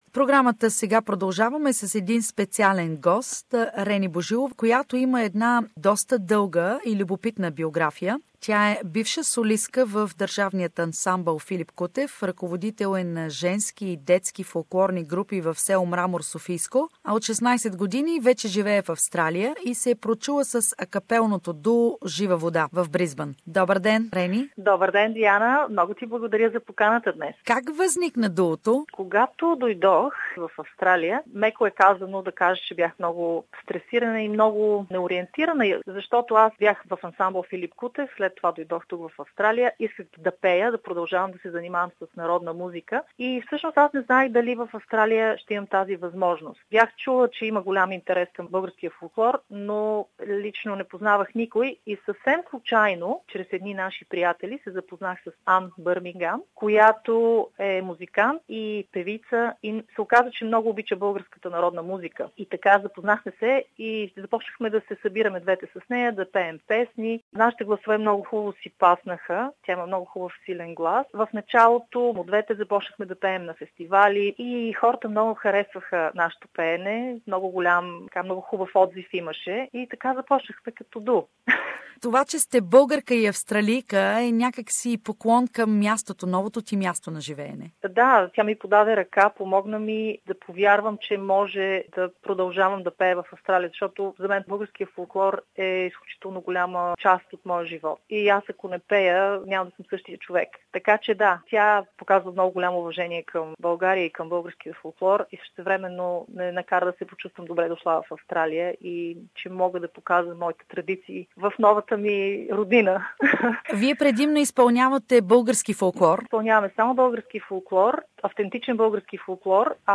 Acapella singing of duo 'Zhiva voda' gives everyone the not thirsty once listen to their songs. Interview